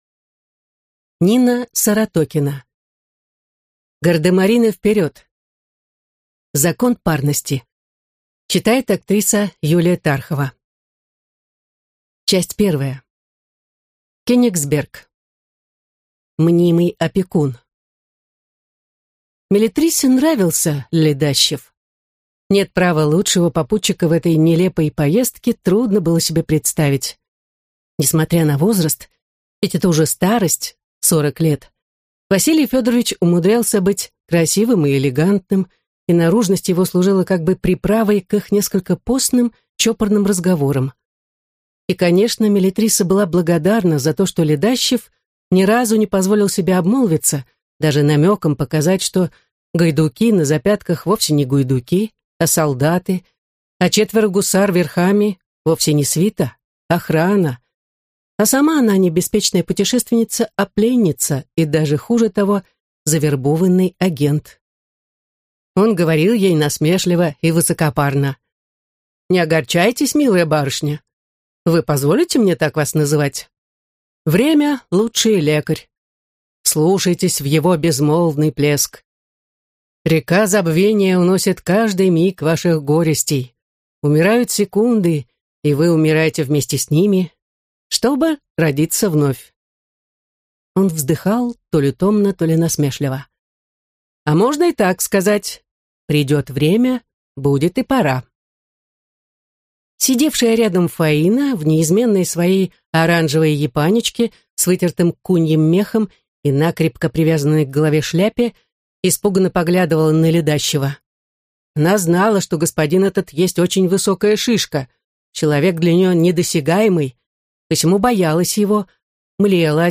Аудиокнига Гардемарины, вперед! Закон парности | Библиотека аудиокниг